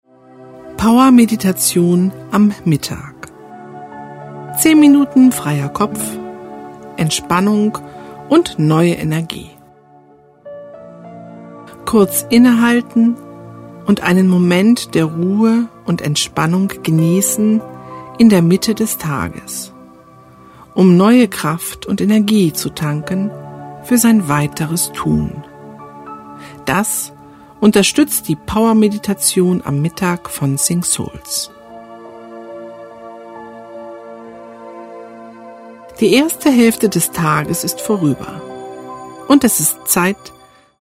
Die begleitende Musik bringt Sie wieder auf Ihre eigene Schwingungsfrequenz. Sie schwingt leicht und wirkt entspannend, belebend und stimuliert den Organismus.
iPhone / iPad / iPod Vorschau Einleitung und Erklärung